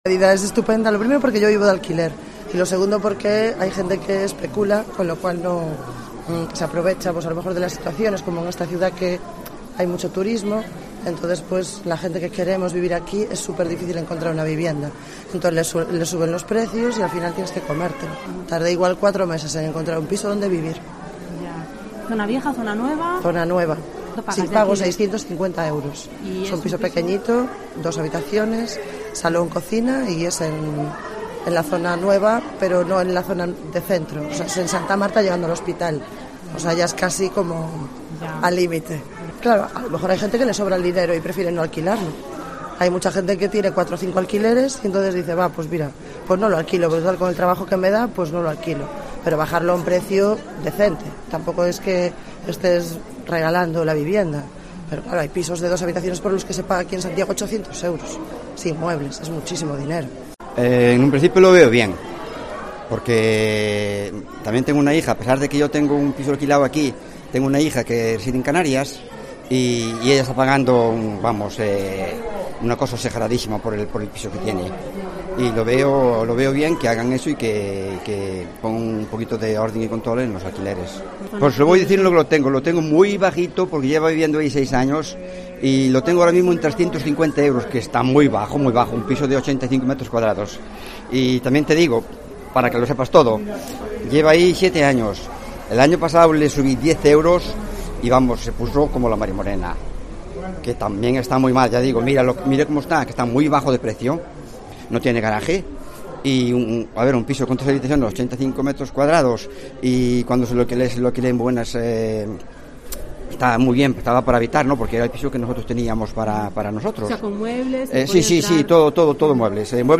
Las dificultades de alquilar en Santiago contadas por los protagonistas